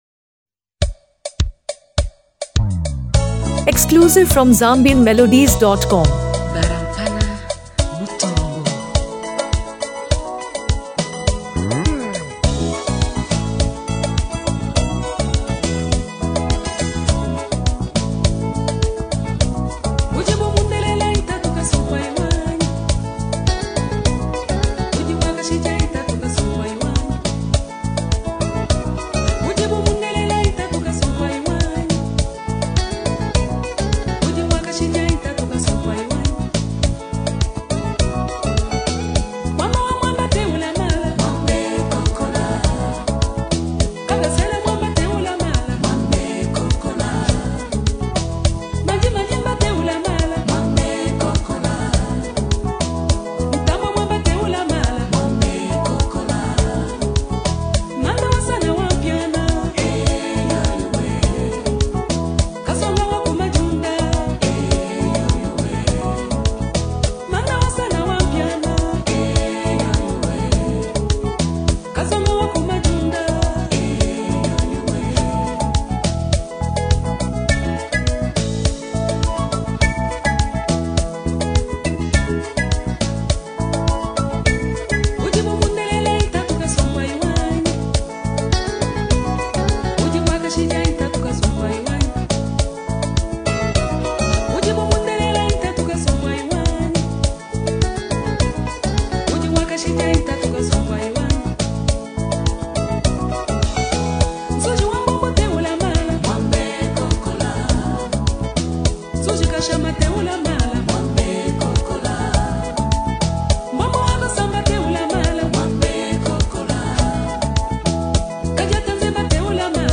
As part of the Afro-traditional and Soukous-inspired genre